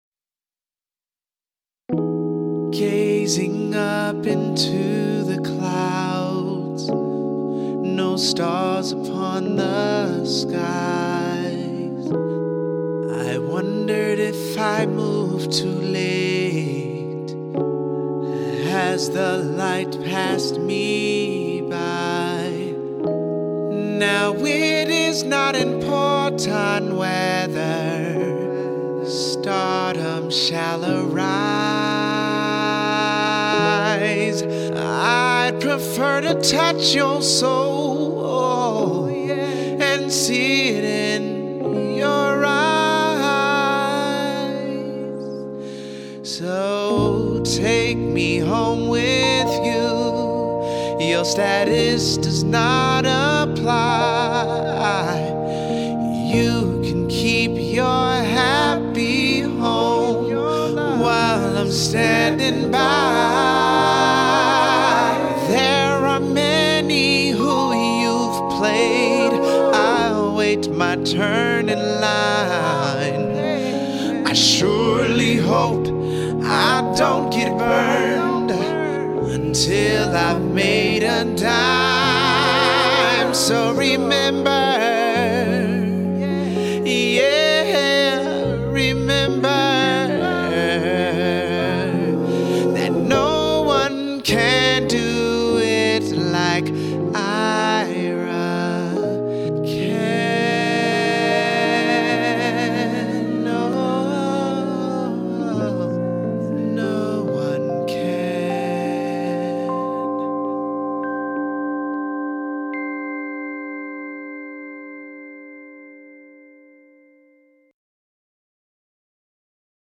Genre: R&B/Soul